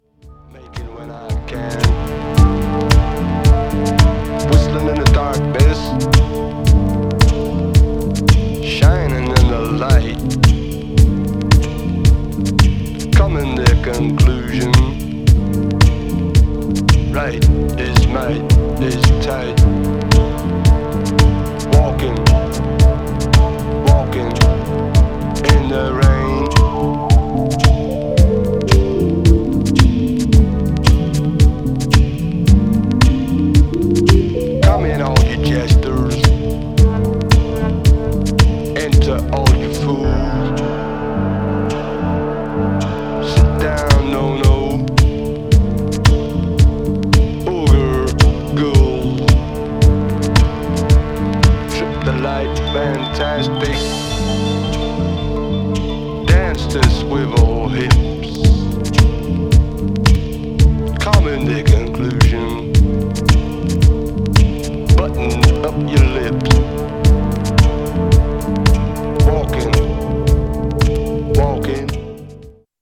Styl: Hip Hop, Lounge